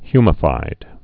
(hymə-fīd)